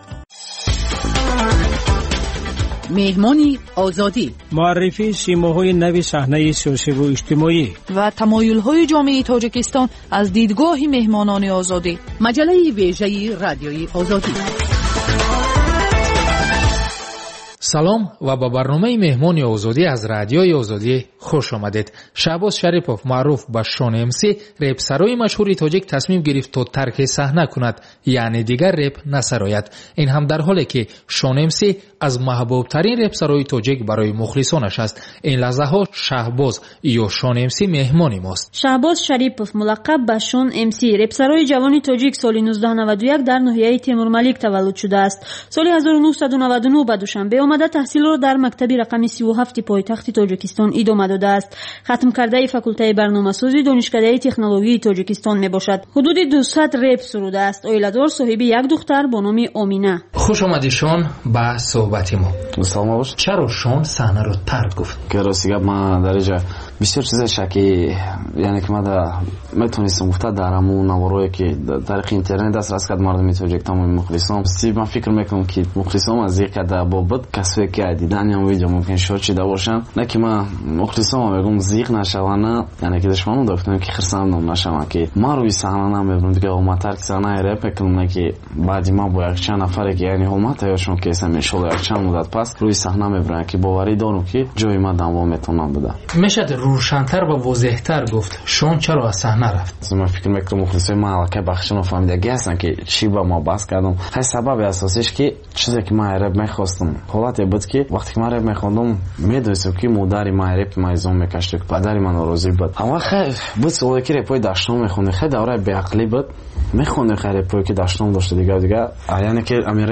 Барномаи мусиқӣ
Навгониҳои мусиқӣ, беҳтарин оҳангҳо, гуфтугӯ бо оҳангсозон, овозхонон ва бинандагон, гузориш аз консертҳо ва маҳфилҳои ҳунарӣ.